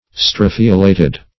Search Result for " strophiolated" : The Collaborative International Dictionary of English v.0.48: Strophiolate \Stro"phi*o*late\, Strophiolated \Stro"phi*o*la`ted\, a. (Bot.)